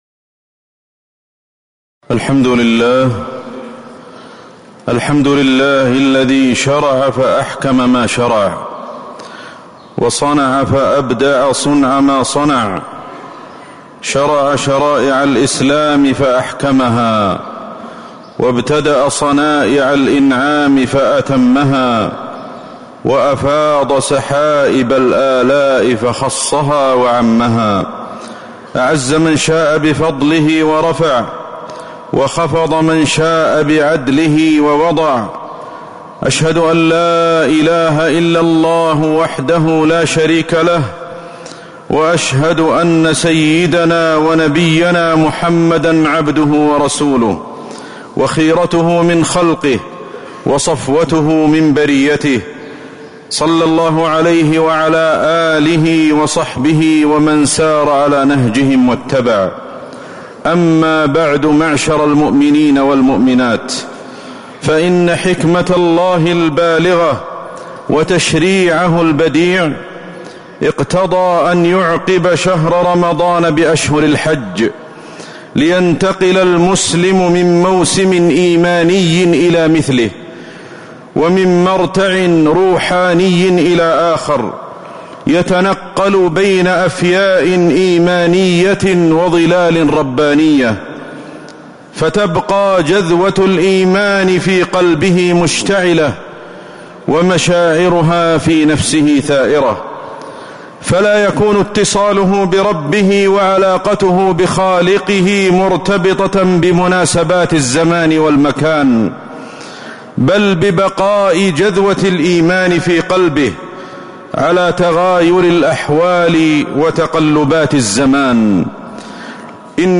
خطبة من حكم تشريع أشهر الحج وفيها: الحكمة من إعقاب أشهر الحج شهر رمضان، وإيناس العبد المؤمن بالعبادة
تاريخ النشر ٢٤ شوال ١٤٤٥ المكان: المسجد النبوي الشيخ: فضيلة الشيخ أحمد الحذيفي فضيلة الشيخ أحمد الحذيفي من حكم تشريع أشهر الحج